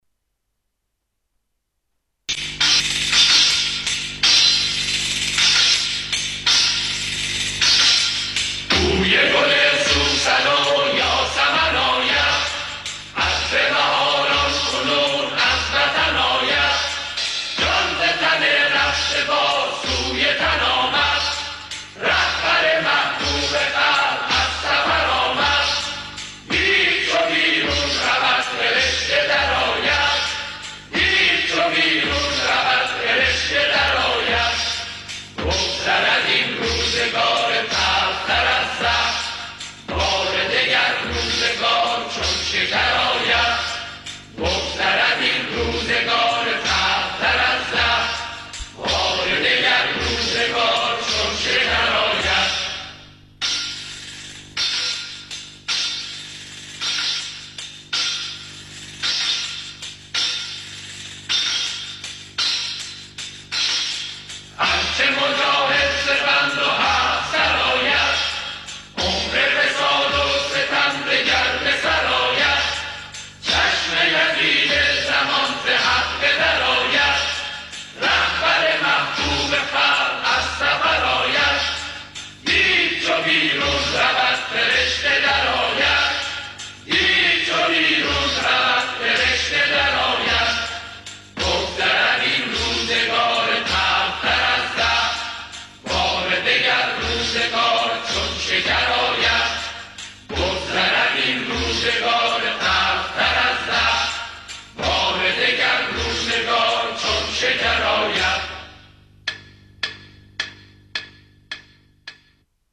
بخشی از سخنان امام خمینی(ره) در بهشت‌زهرا: